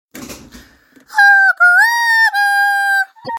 Hog Rider High Pitch - Botón de Efecto Sonoro